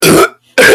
Memes
Two Demonic Burps